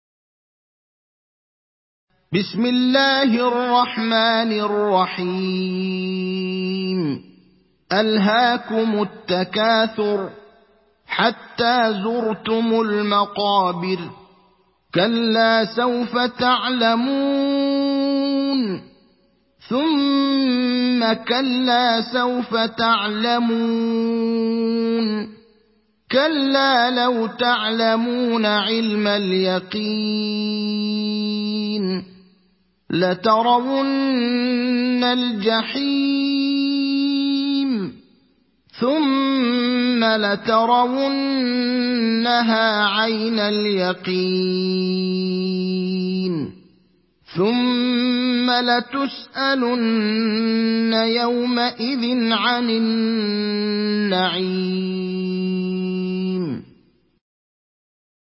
تحميل سورة التكاثر mp3 بصوت إبراهيم الأخضر برواية حفص عن عاصم, تحميل استماع القرآن الكريم على الجوال mp3 كاملا بروابط مباشرة وسريعة